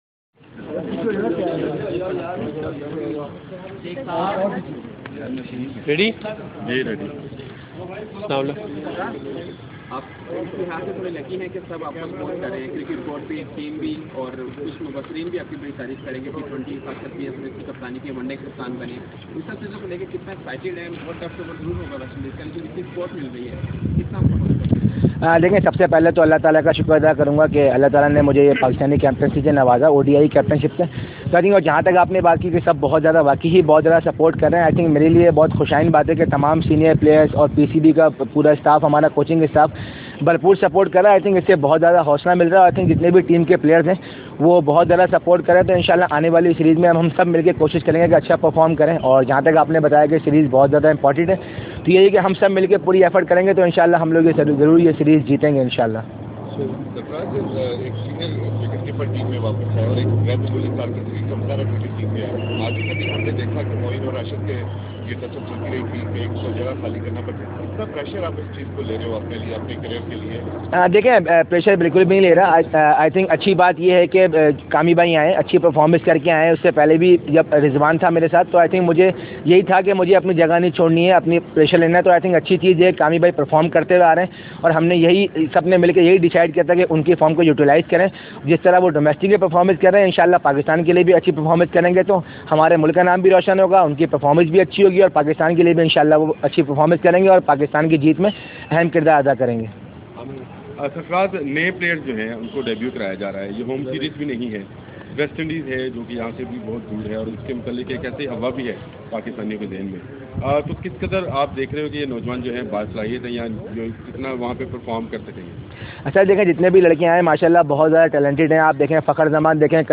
Sarfaraz Ahmed media talk at GSL (Audio)